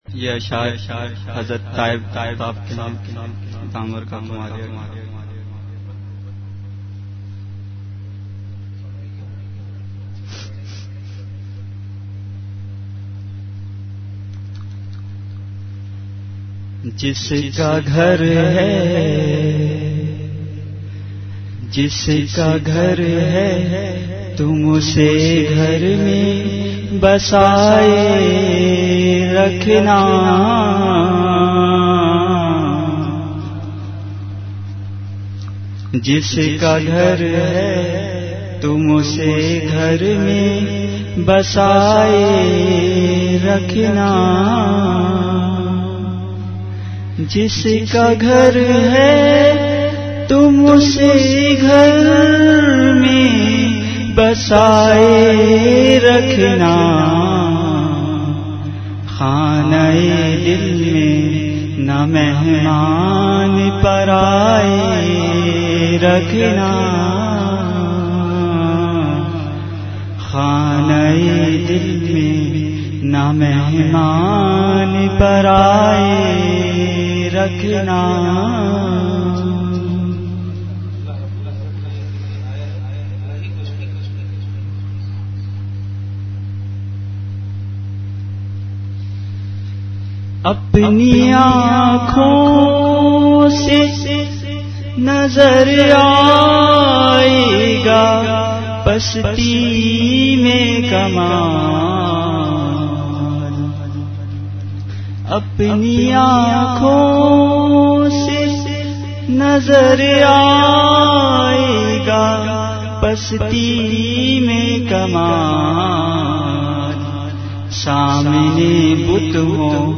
Delivered at Home.
Category Majlis-e-Zikr